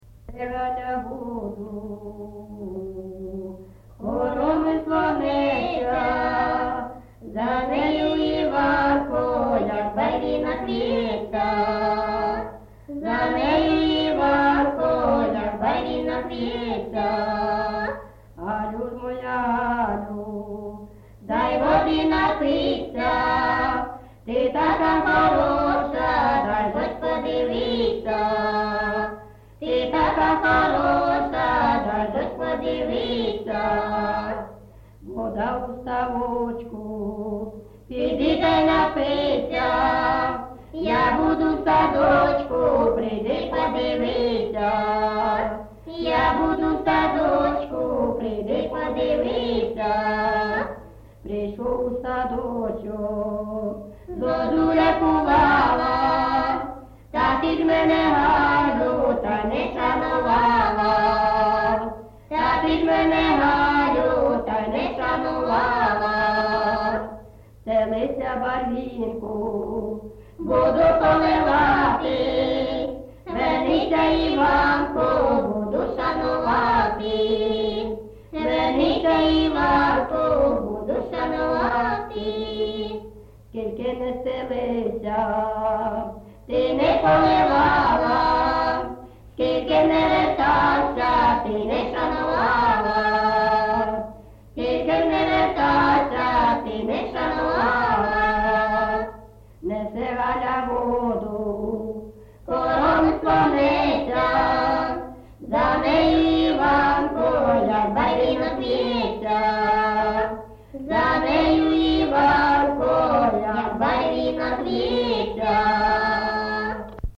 ЖанрПісні з особистого та родинного життя, Пісні літературного походження
Місце записус. Золотарівка, Сіверськодонецький район, Луганська обл., Україна, Слобожанщина